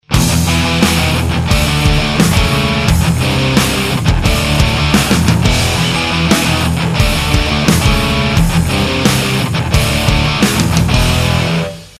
Нажмите для раскрытия... возможно, но именно это не постоянное гудение, а какое то с паузами небольшими, точно такое же, как и фон в тракте.